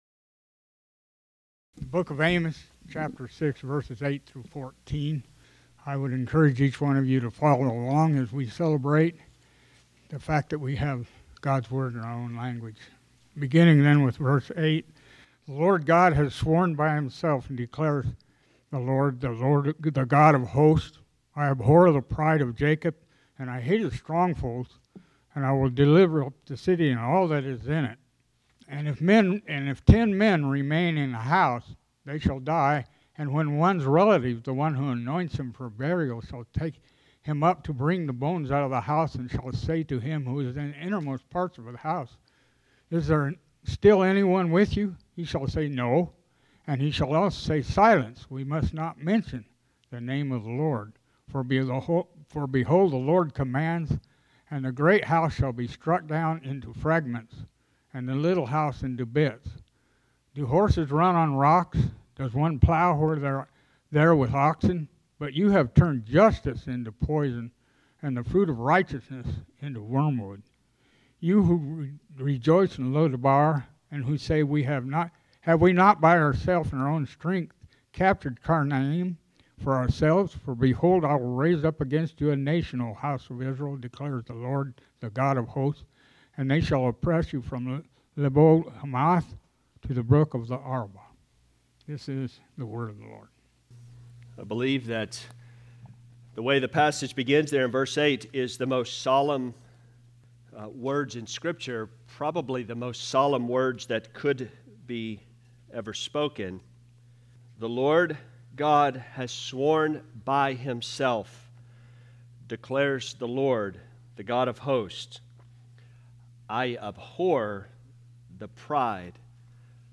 Pride’s Reality Check Sermon